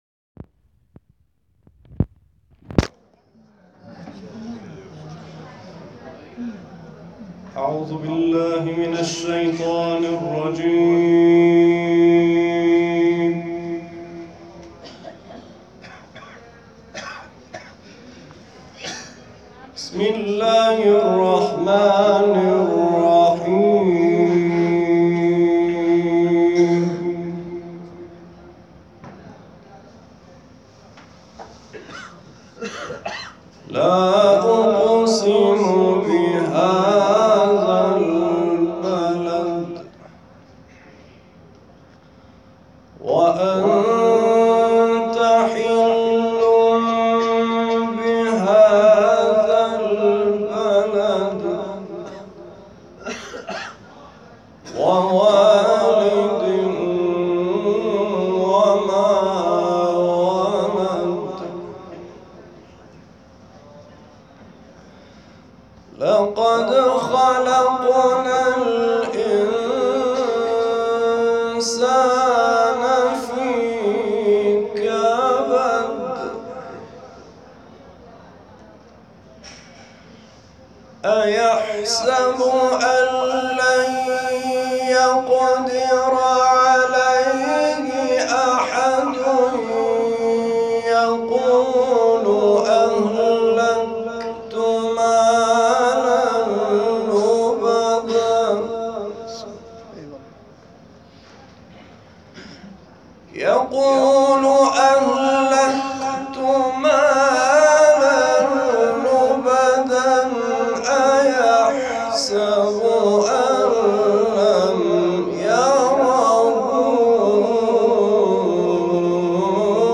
6 سوره بلد   /  مدینه  - حج 98